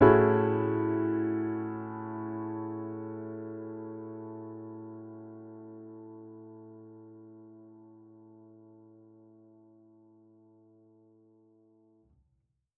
Index of /musicradar/jazz-keys-samples/Chord Hits/Acoustic Piano 1
JK_AcPiano1_Chord-Amaj13.wav